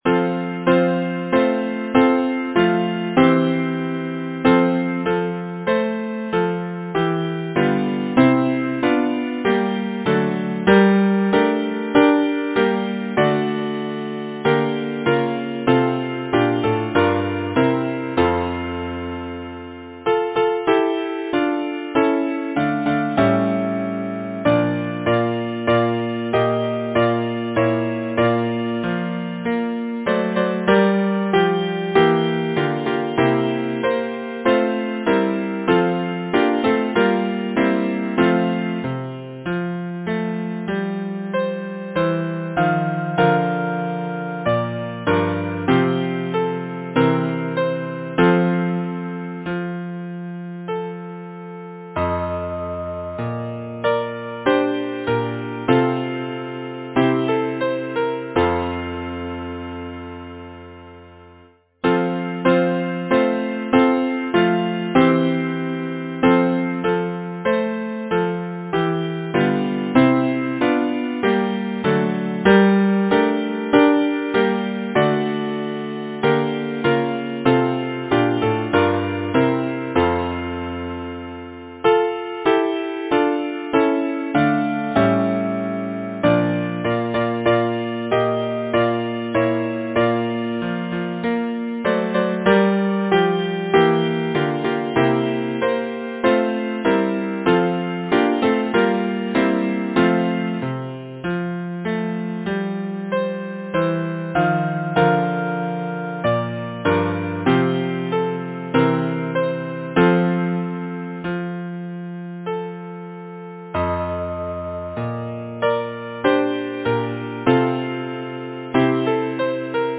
Number of voices: 4vv Voicings: SATB or ATTB Genre: Secular, Partsong
Language: English Instruments: Keyboard